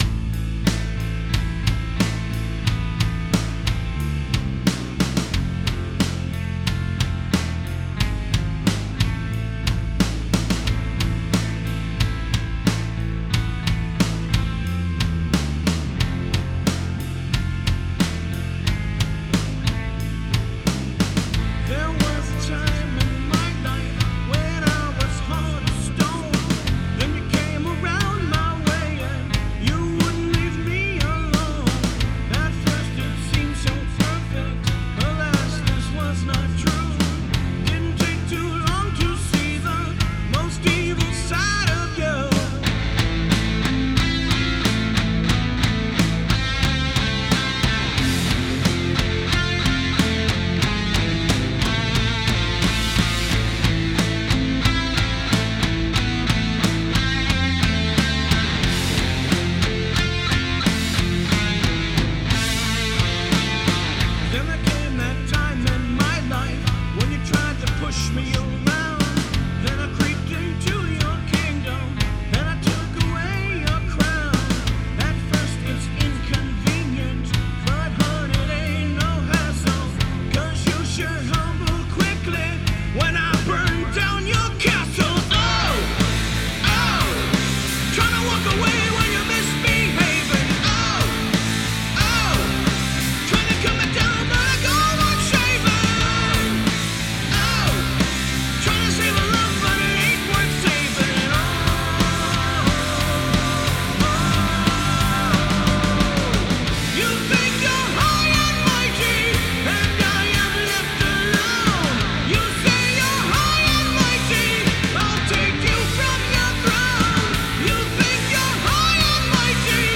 I set up a tiny budget studio in the corner of my bedroom and I wanted to get some feedback on my first real project.
The vocals I think are a little boxy still, and I'm not sure what frequency to slice out to get rid of it.
Changed some drum sounds, adjusted some volumes, have a separate reverb channel automated for the lead vocals, gave a little back to the high pass on the guitars and nudged up the bass.